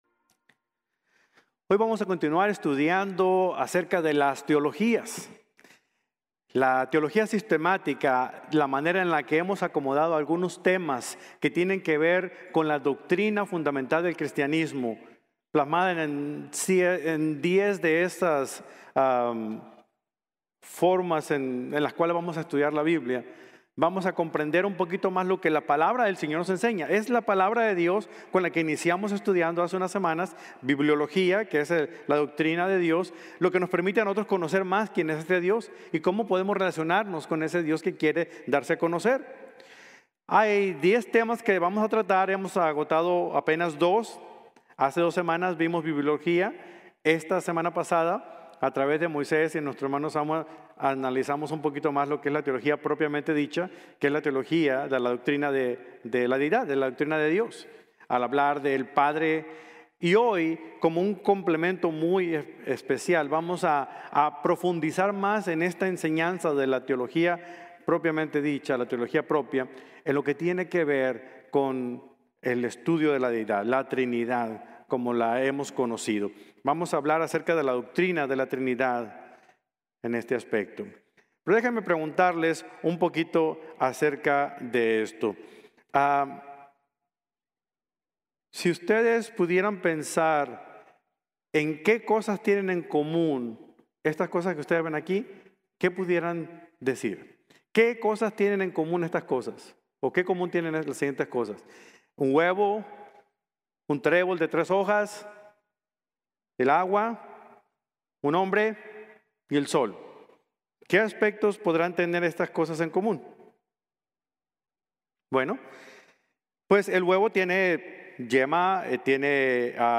La Trinidad | Sermon | Grace Bible Church